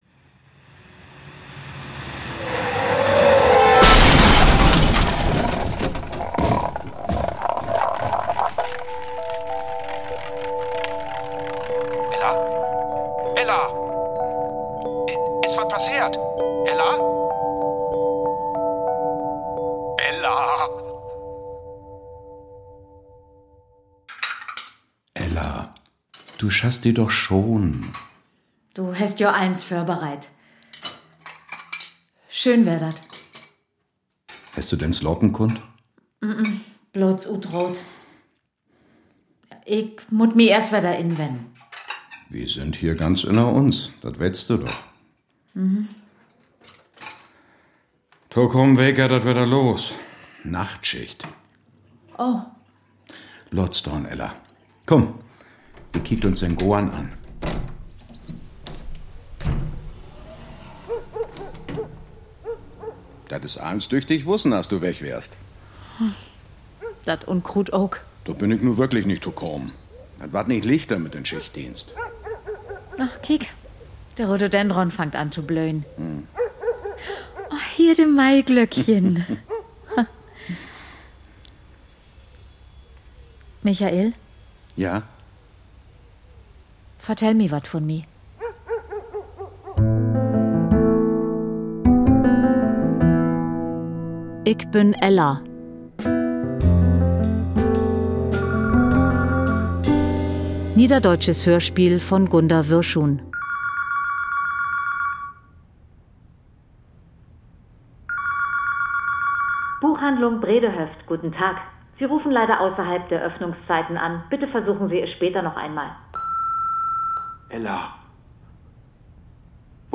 Ick bün Ella ~ Das Niederdeutsche Hörspiel Podcast